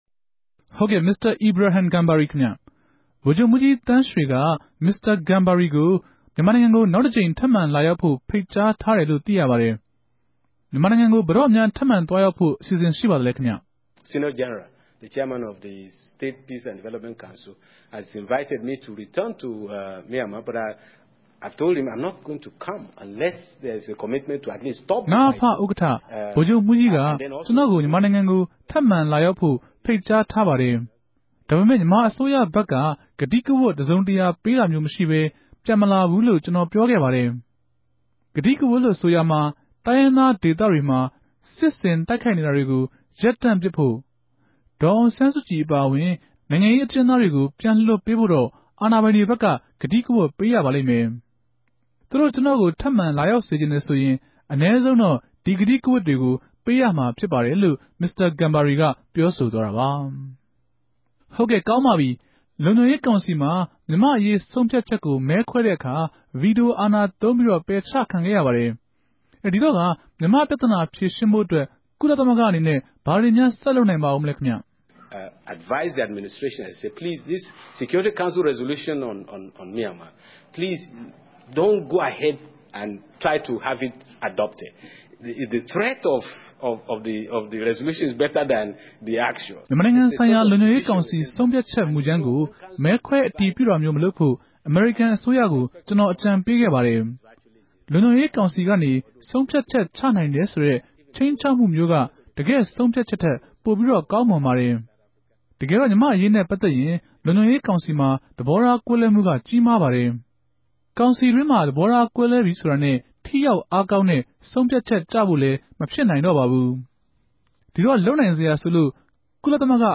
ကုလသမဂ္ဂ အထူးအုကံပေးအရာရြိံြင့် RFA သီးသန်ႛအင်တာဗဵြး